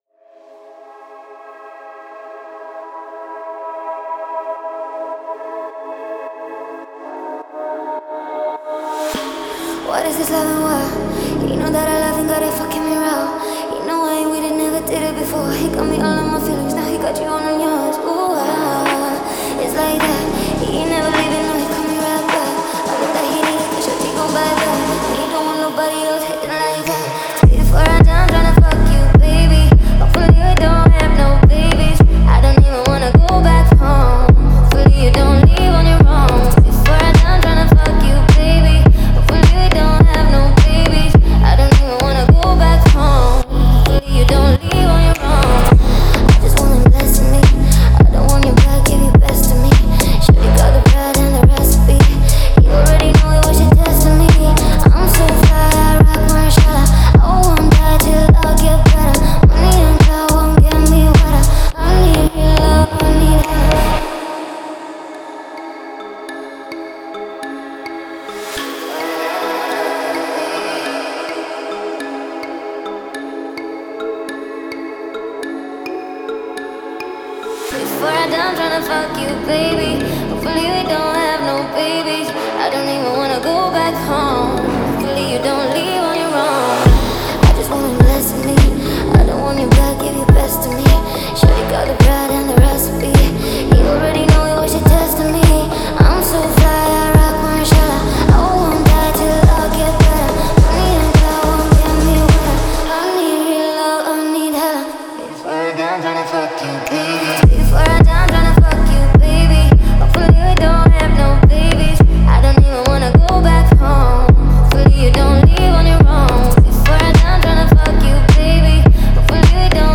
это трек в жанре EDM
мощный вокал